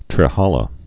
(trĭ-hälə)